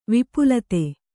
♪ vipulate